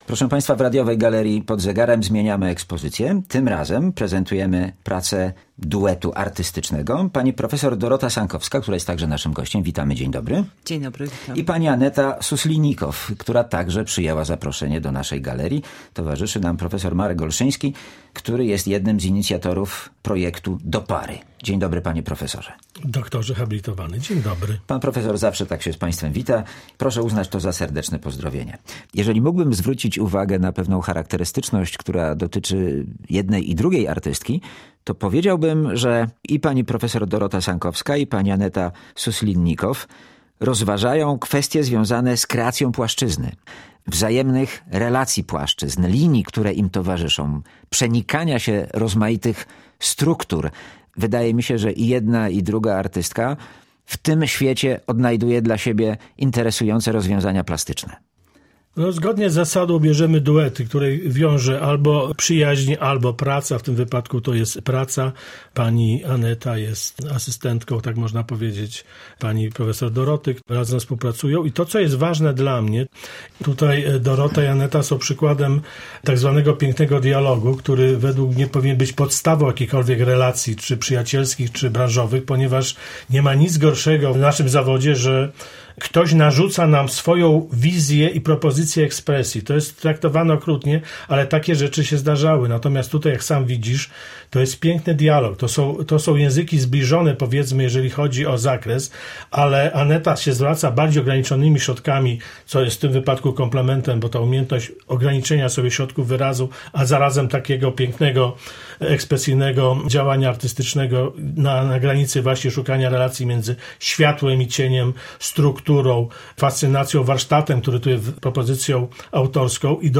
O prezentowanych dziełach i pracy artystycznej rozmawiają